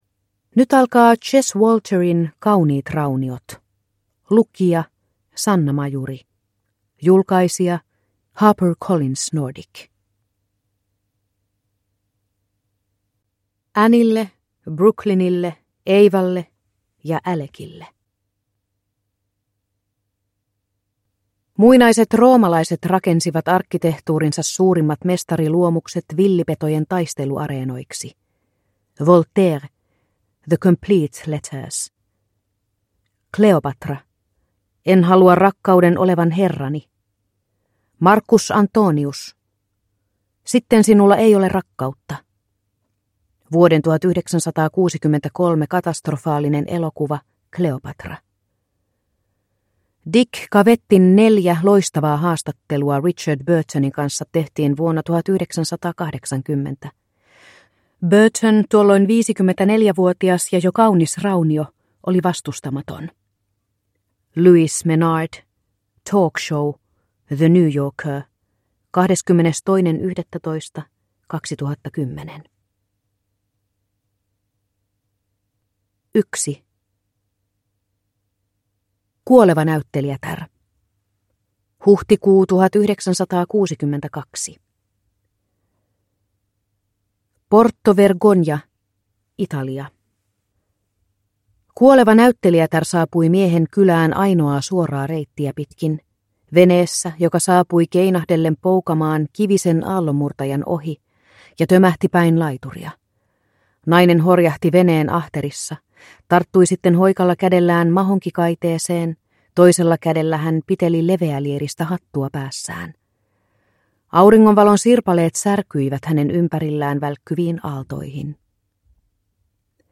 Kauniit rauniot – Ljudbok – Laddas ner